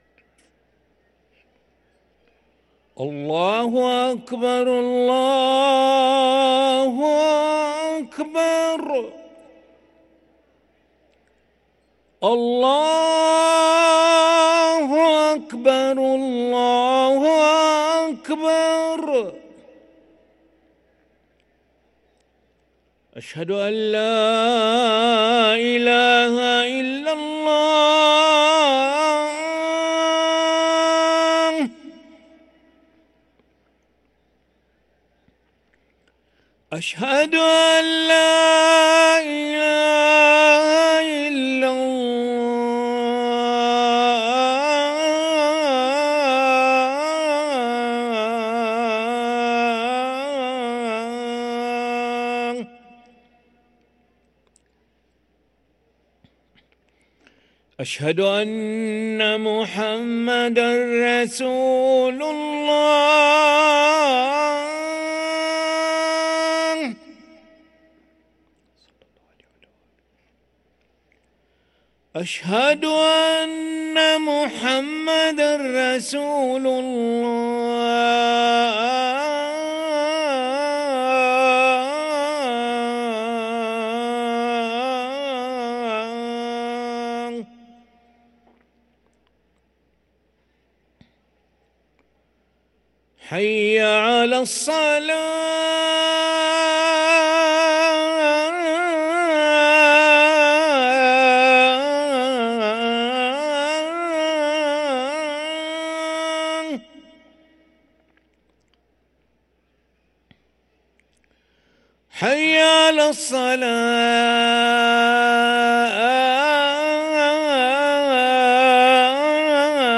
أذان العشاء للمؤذن علي أحمد ملا الأحد 15 ذو القعدة 1444هـ > ١٤٤٤ 🕋 > ركن الأذان 🕋 > المزيد - تلاوات الحرمين